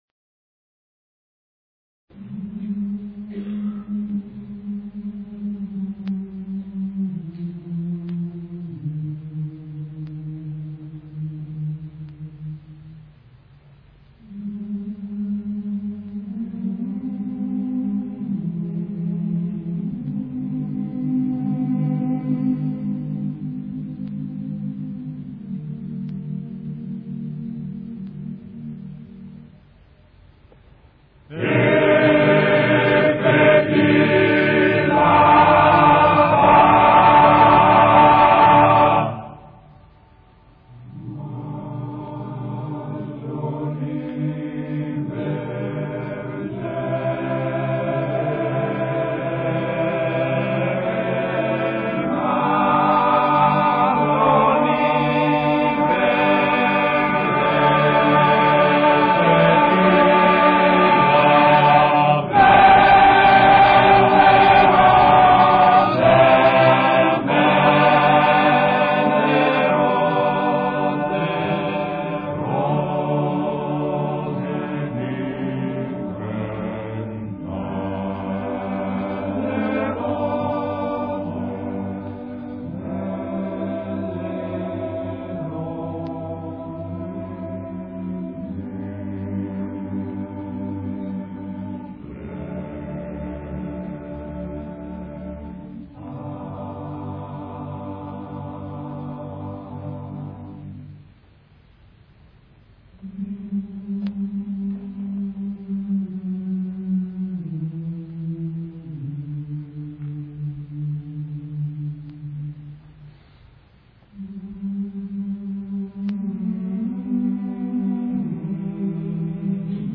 Belle rose du printemps [ voci virili ] Que fais-tu là-bas ma jolie bêrgère?